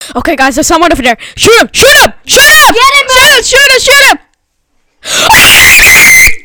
Sound Effects
Fortnite Scream